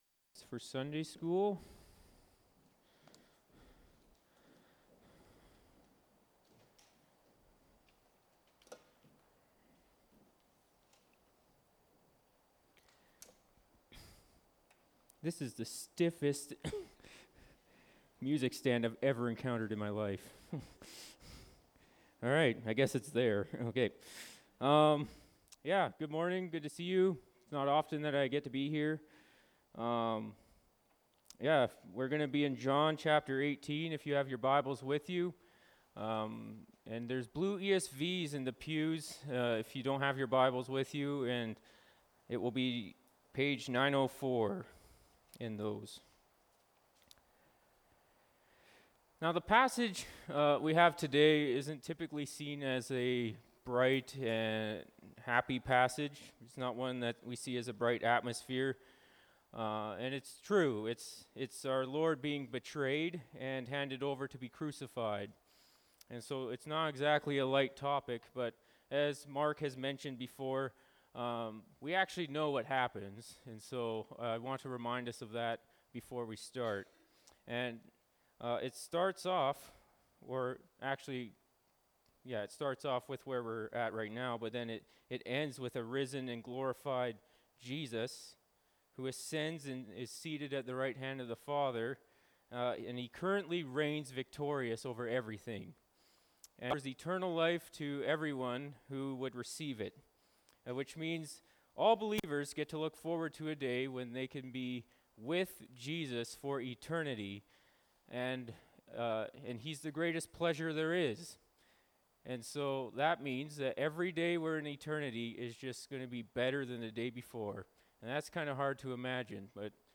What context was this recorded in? Jun 02, 2024 The King and the Robber (John 18:28-40) MP3 SUBSCRIBE on iTunes(Podcast) Notes Discussion Sermons in this Series This sermon was recorded in Salmon Arm and preached in both Grace Church campuses.